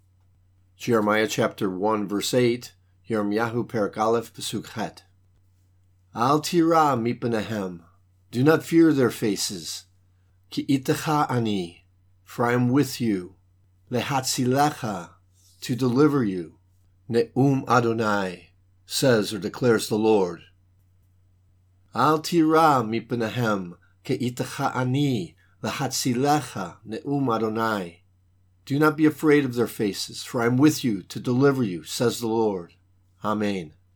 Hebrew Lesson
Jeremiah 1:8 reading (click):